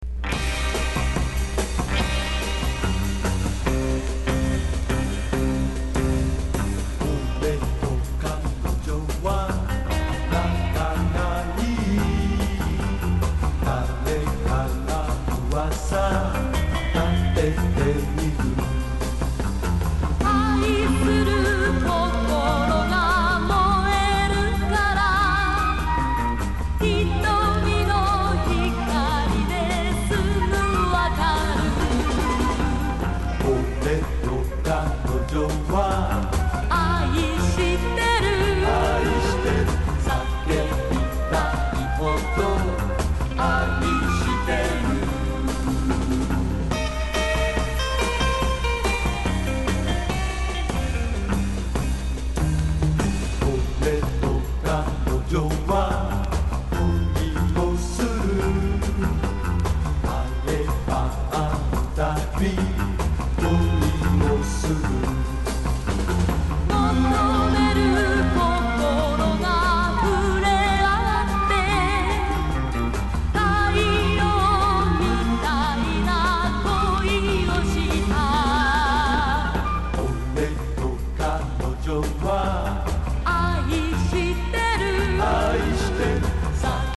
60-80’S ROCK# POP